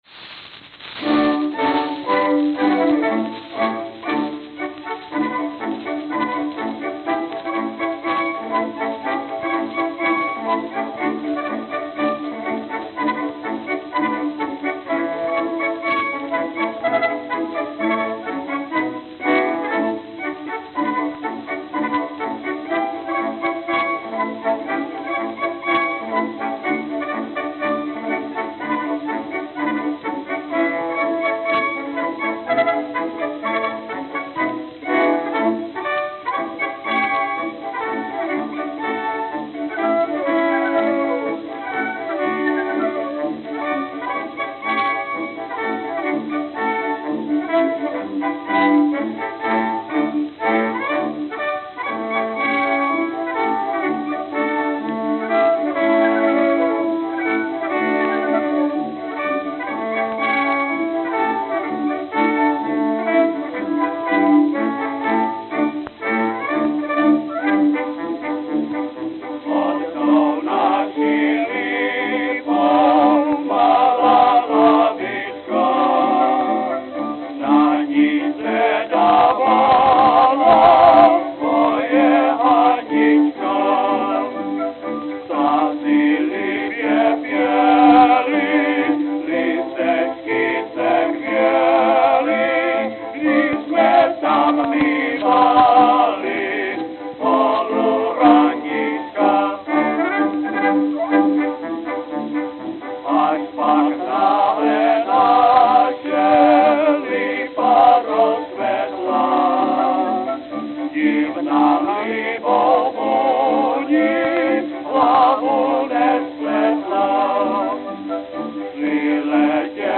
New York, New York New York, New York
Groove wear at loud passages.